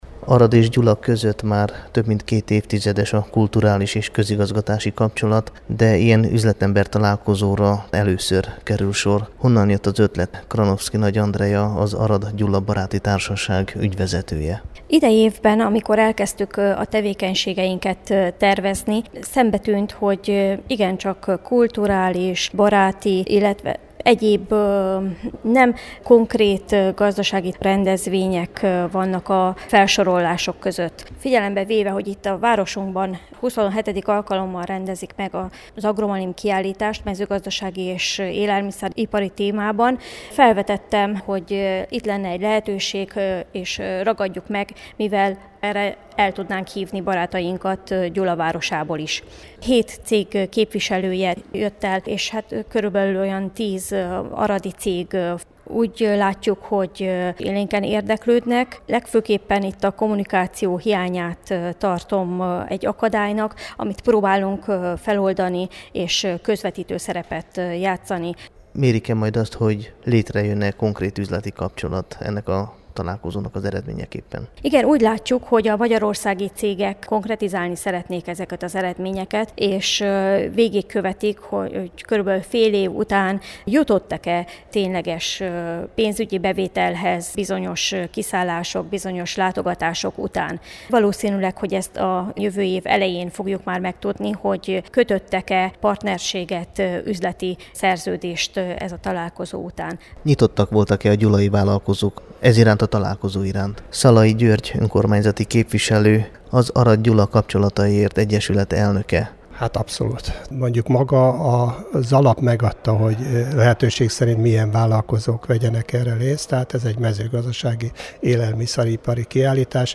Pénteken megtartották az első üzletember-találkozót aradi és gyulai vállalkozók részvételével. Erre az Aradon zajló Agromalim nemzetközi mezőgazdasági vásár nyújtott alkalmat, amelyen nagy számban képviseltetik magukat magyarországi cégek.